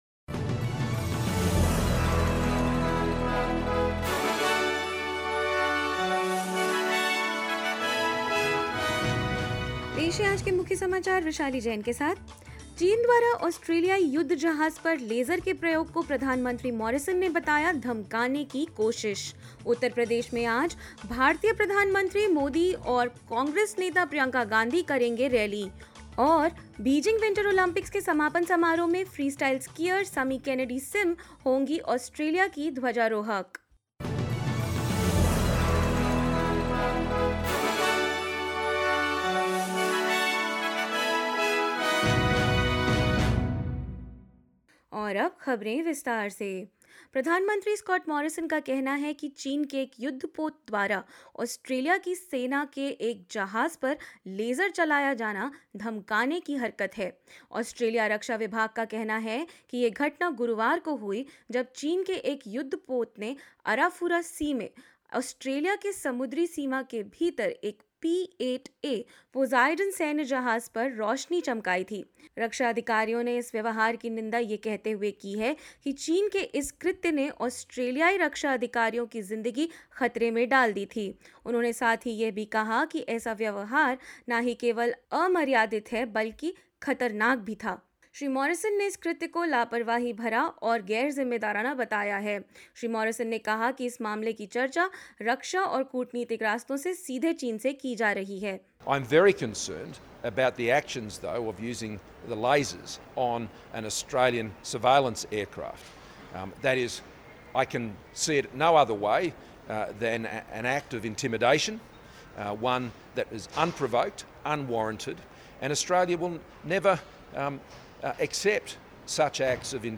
SBS Hindi News 20 February 2022: Australia condemns lasering of an Australian aircraft by a Chinese ship
hindi_news_2002.mp3